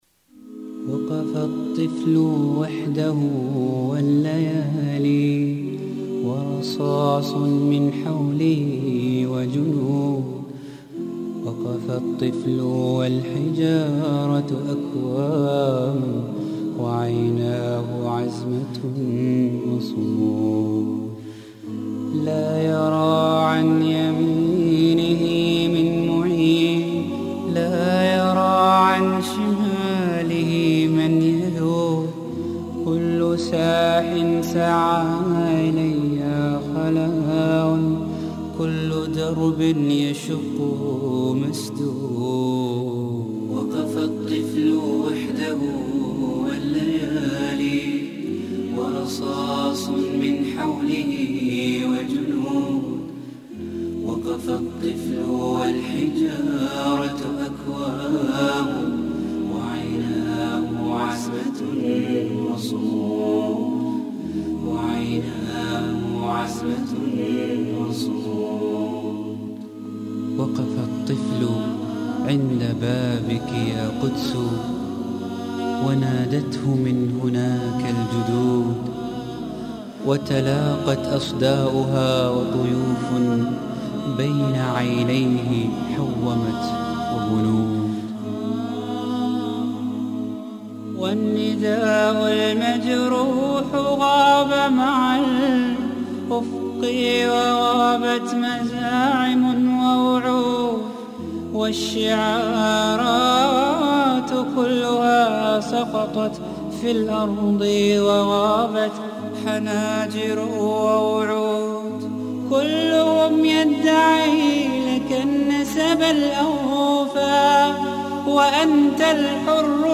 Arabic Islamic Songs